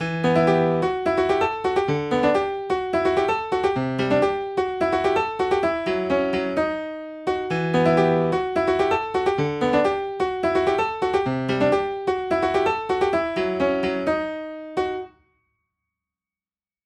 和音を無くしクォンタイズをかけて音量を一定にする
次に和音の部分は音をズラすなどして重ならないようにして、寄れた音符にクォンタイズでカッチリとしたタイミングで鳴るようにします。
音量も一定に鳴るように調整します。